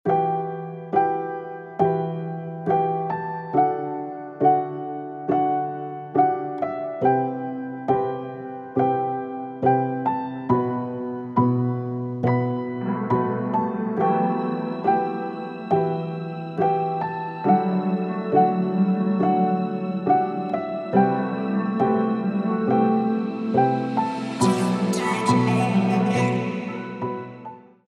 грустные
красивая мелодия
пианино
печальные
Погружает в мысли